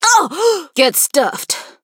BS_juju_hurt_vo_03.mp3